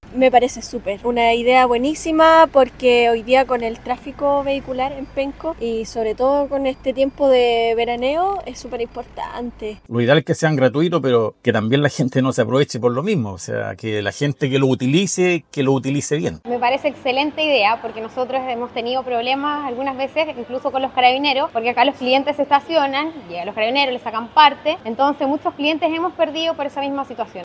Conductores y locatarios valoraron la medida, indicando que ayudará a atraer clientes que antes se arriesgaban a infracciones.